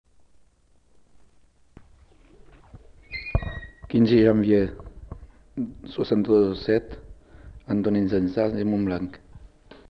Annonce par le collecteur
Aire culturelle : Savès
Lieu : Monblanc
Genre : parole